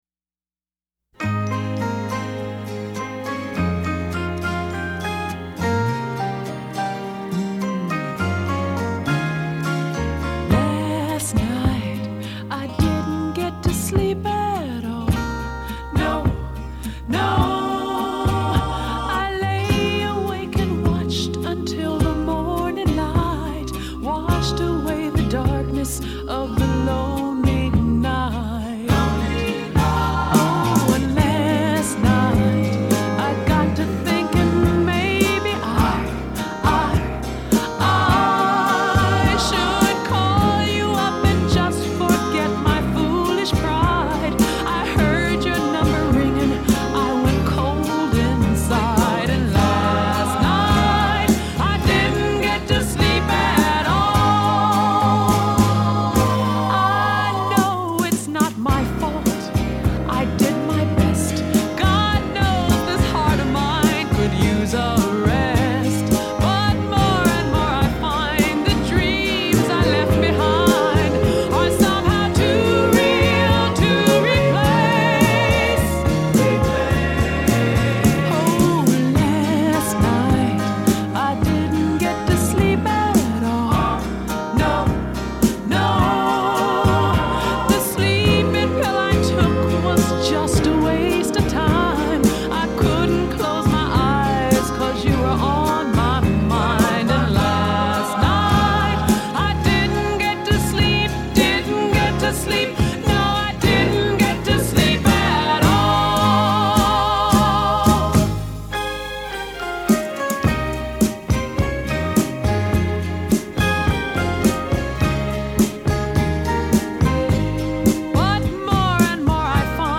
R&B/soul